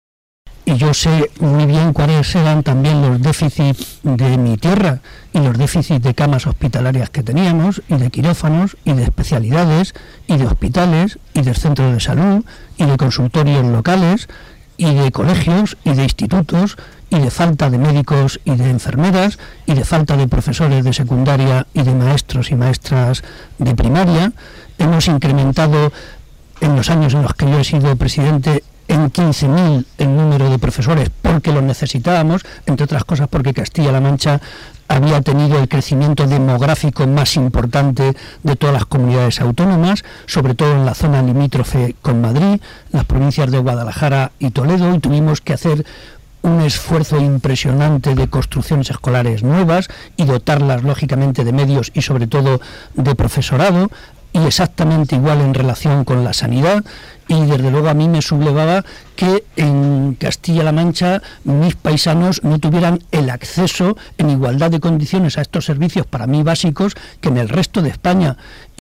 El Presidente del Grupo Parlamentario y secretario general de los socialistas de Castilla-La Mancha, José María Barreda, ha realizado una entrevista en el programa radiofónico “Herrera en la Onda”, de Onda Cero.